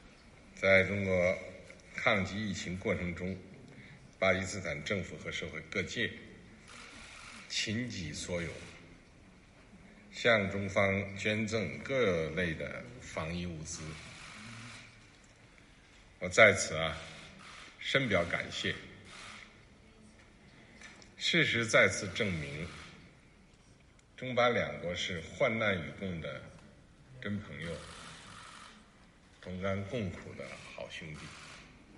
音频：习近平主席在会谈中对阿尔维总统说，事实再次证明，中巴两国是患难与共的真朋友、同甘共苦的好兄弟。